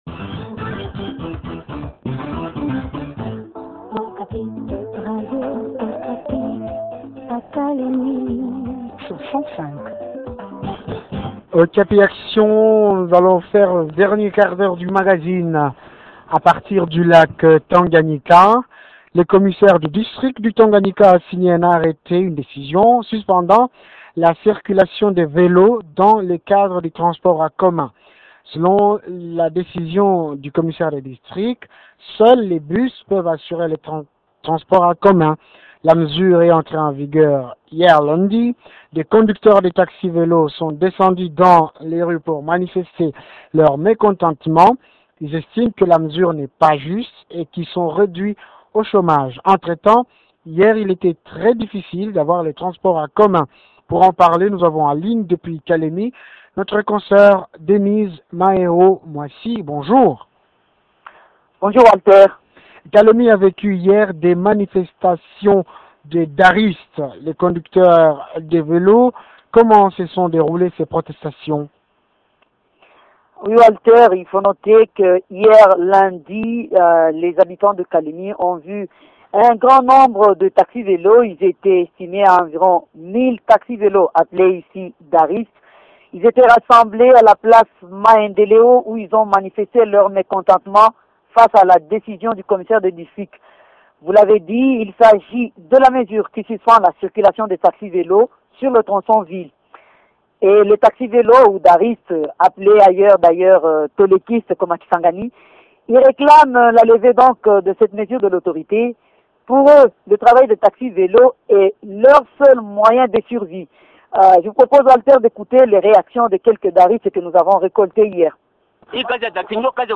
reçoit Frédéric Yuma, Administrateur du territoire.